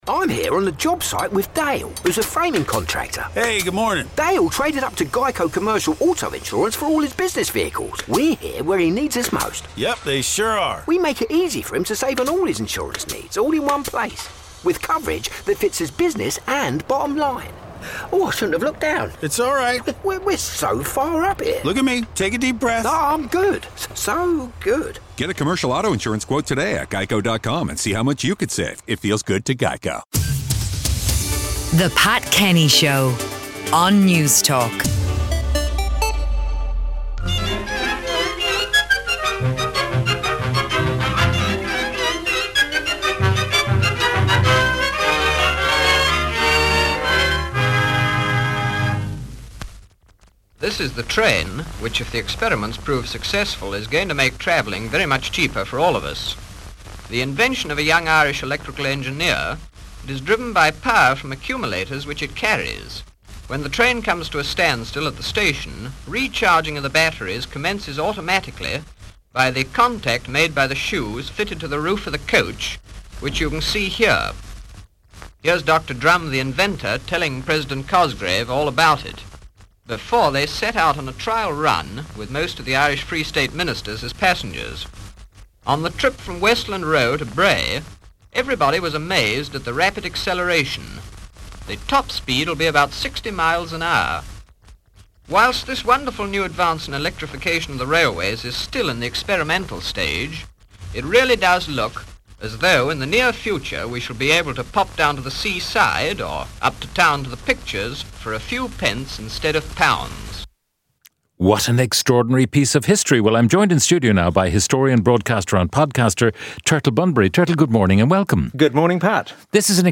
Pat is joined by historian, broadcaster and podcaster, Turtle Bunbury, to look back at the history of train travel in Ireland as we recount some of the most intriguing locomotives to ever take to the tracks.